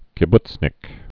(kĭ-btsnĭk, -bts-)